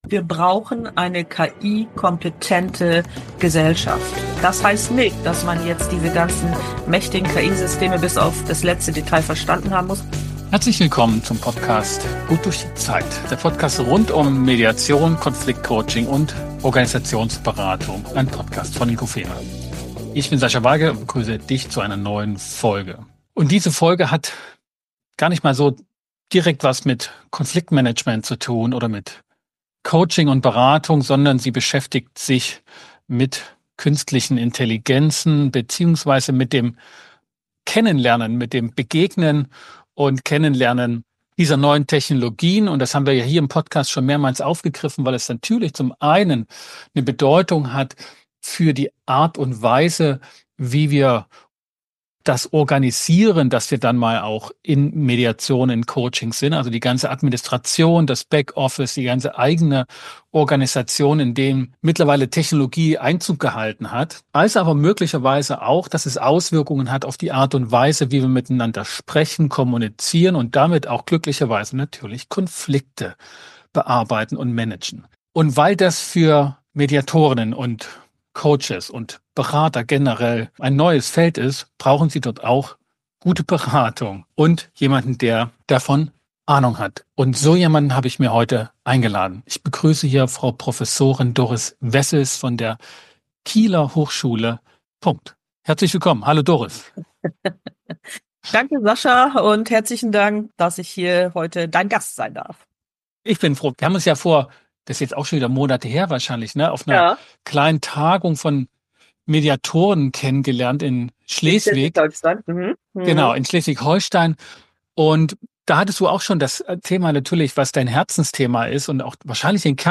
1 #201 GddZ - Das vertikale und das horizontale Kommunikationssystem. Im Gespräch